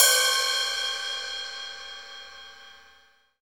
HAT ROCK 0PR.wav